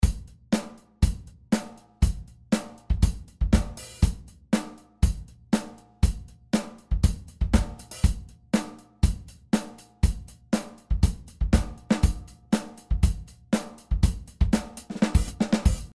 Uhbik-D – Delay
Uhbik-D liefert einen überzeugenden, warmen Klang, der nach Bandecho klingt und den man mittels Kuhschwanzfilter für die Absenkung von Bässen und Höhen einengen kann.